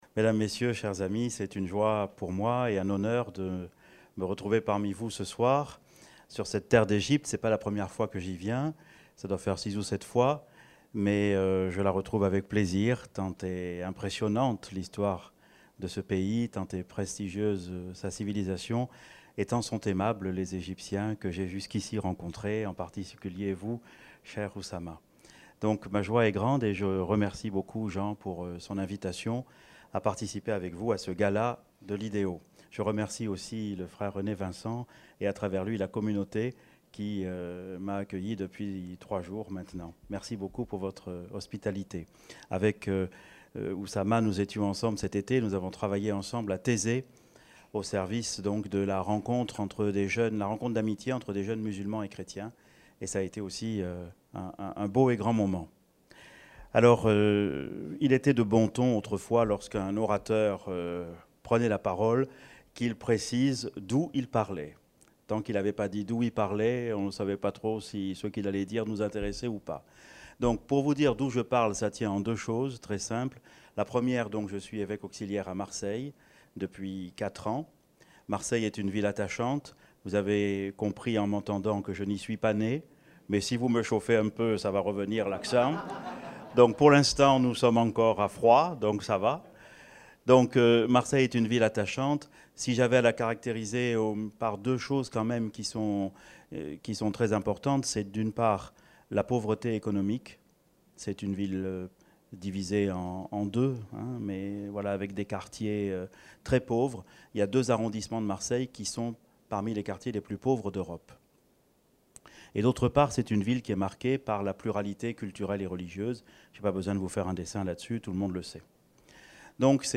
Listen to the lecture (in French):